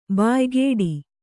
♪ bāygēḍi